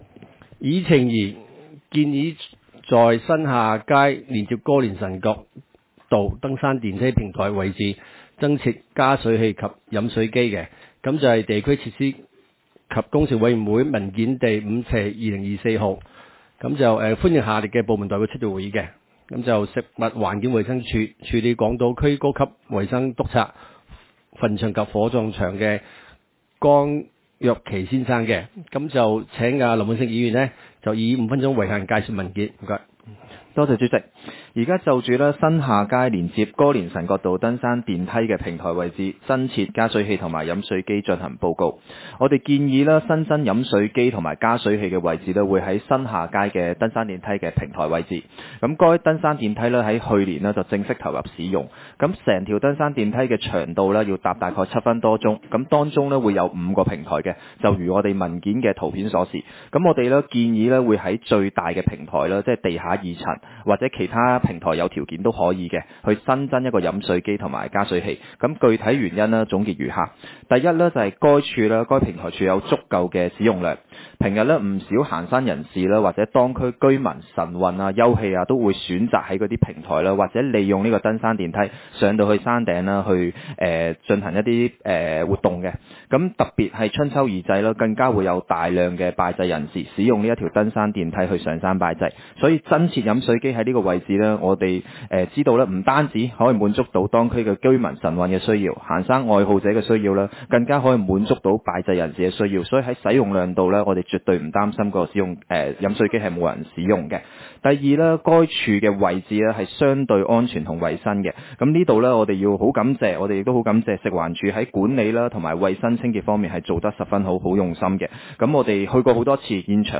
委員會會議的錄音記錄